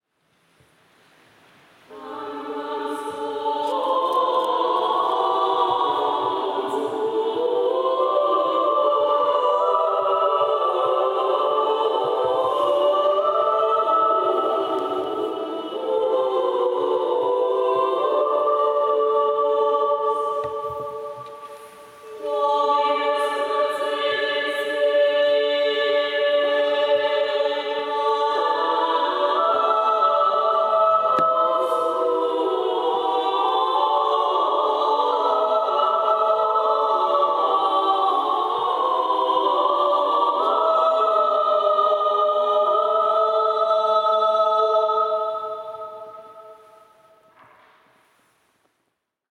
Koncer pasyjny na Kamionku - reportaże i wspomnienia
Każda z wykonawczyń miała sposobność solowego zaprezentowania swojego kunsztu wokalnego.
Każdy głos z osobna i w wielogłosie ukazał nie tylko profesjonalizm śpiewaczy, ale również znajomość specyfiki wokalistyki dawnych wieków.
Tamquam sponsus – trzygłosowa antyfona z XV wieku (Śląsk).